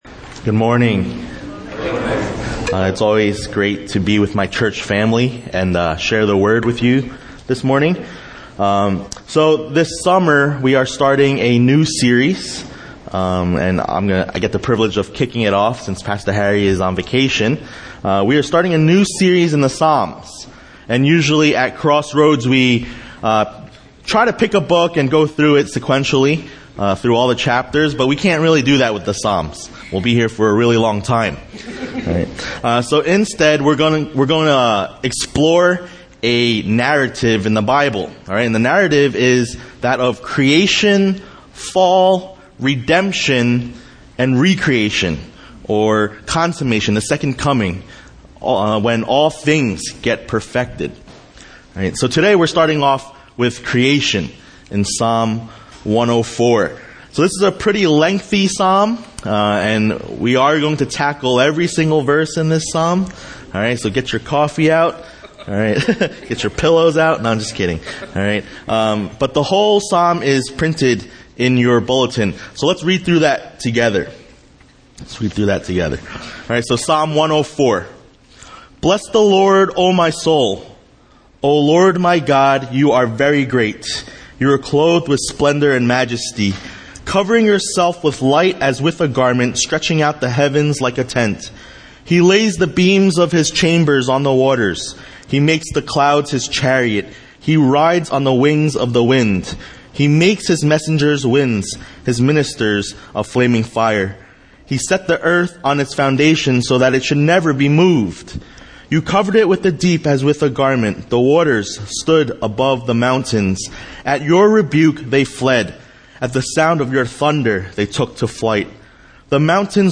A message from the series "General Topics."